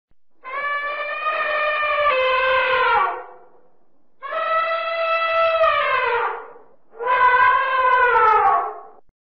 Sonido_de_Elefante_-_Sonidos_de_Animales_para_ninos.mp3